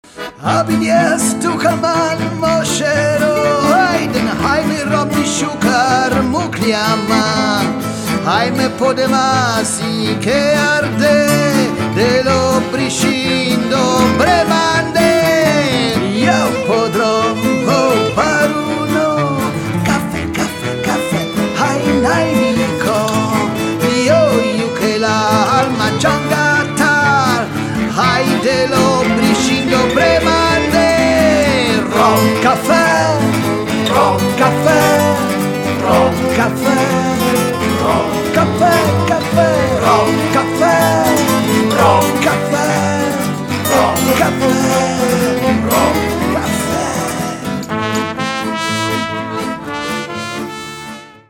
mandola
Genere: Musica Popolare